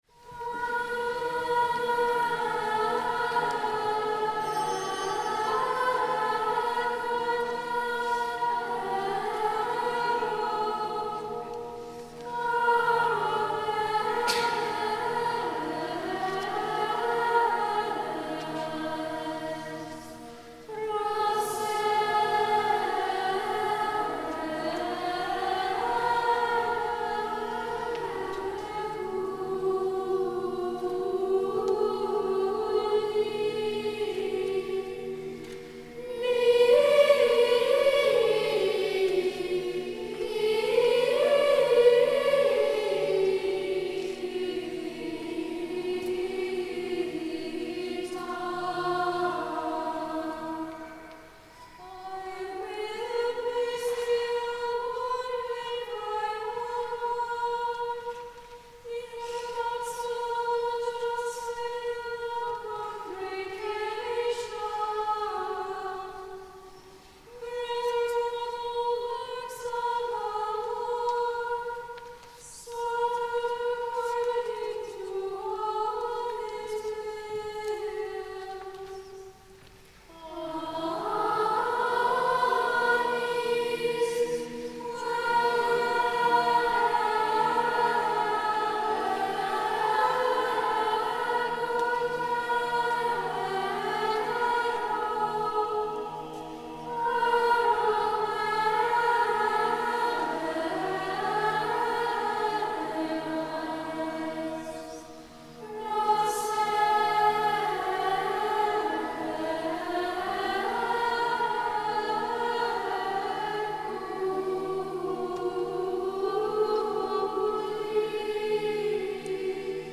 This past June, our parish hosted a week-long Chant Camp for children and teens ages 8 to 17.
Mp3 Download • Live Rec. (Chant Camp Mass)
—“Pánis, quem égo dédero” • Chant Camp Mass, Ave Maria Catholic Church – Ave Maria, FL.
On the final day of camp, these 60 young voices filled the church with the sacred sounds of the full Gregorian chant Propers, and the complete Missa Orbis Factor (Mass XI).